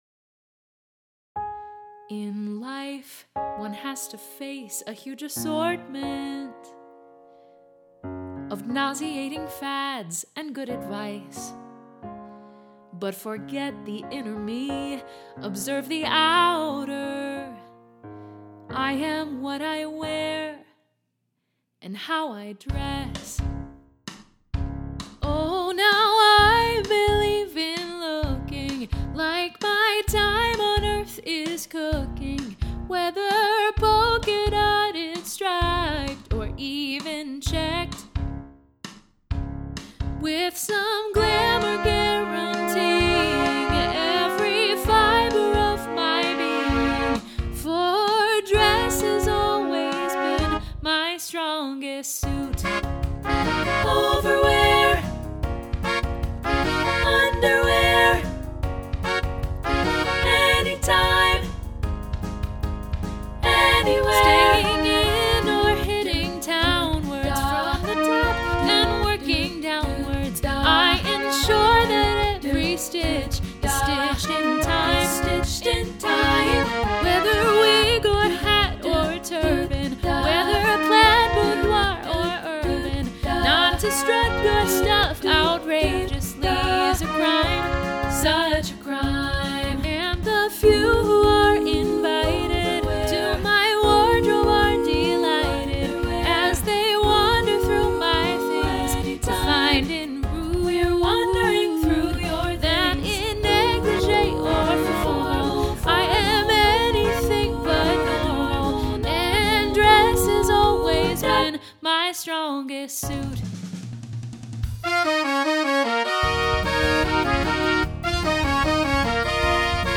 contemporary choral SSA arrangement